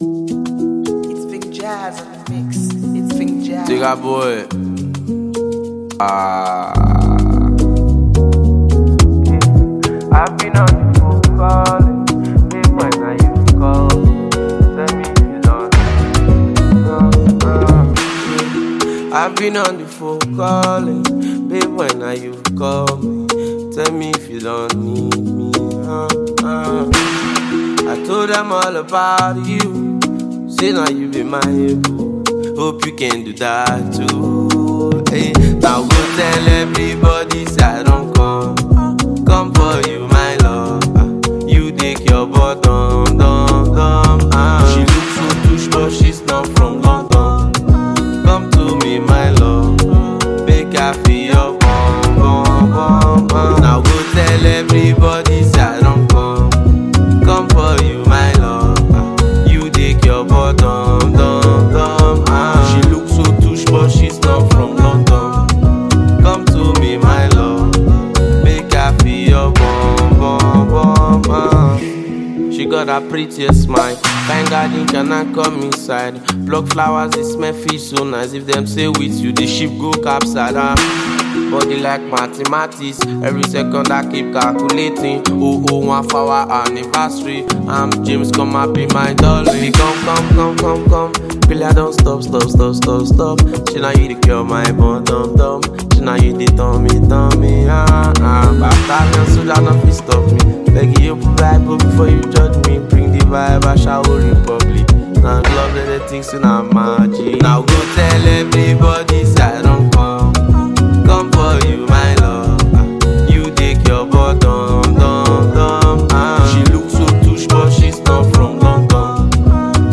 groovy new single